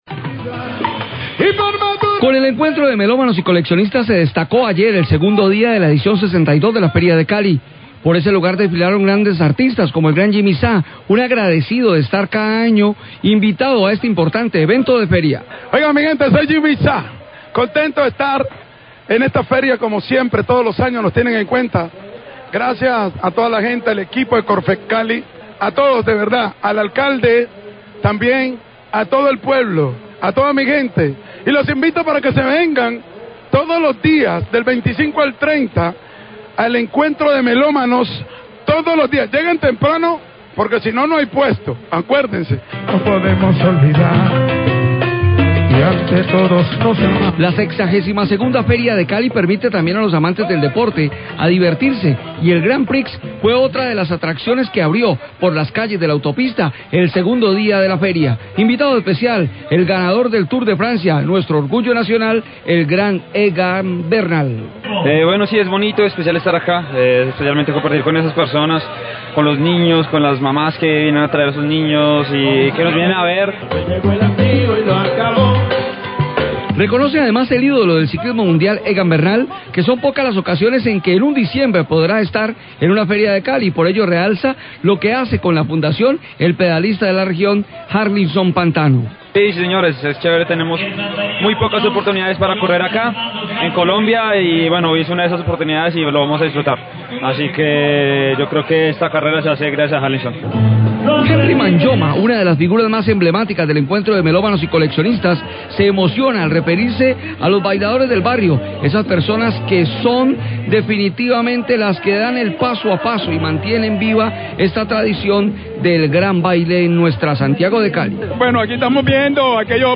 Radio
Crónica periodistica de lo vivido en la programación del segundo día de la Feria de Cali.